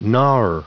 Prononciation du mot gnawer en anglais (fichier audio)
Prononciation du mot : gnawer